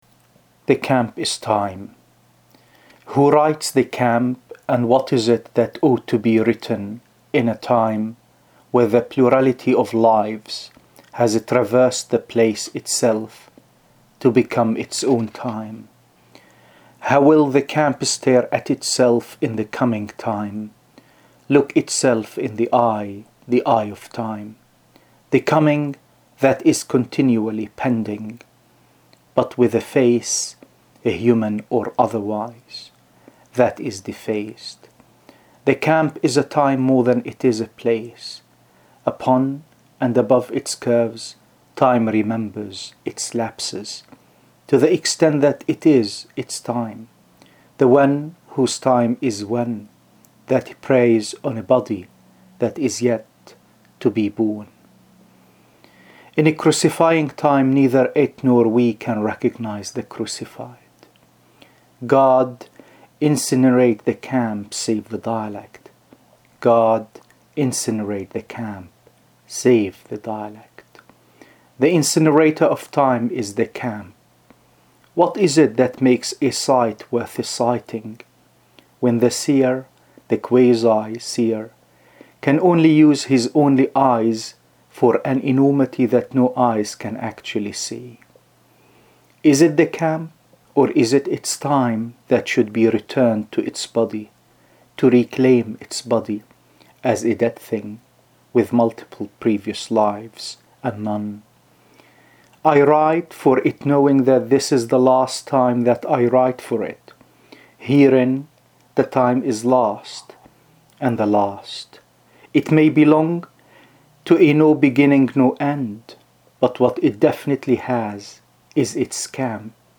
read his poem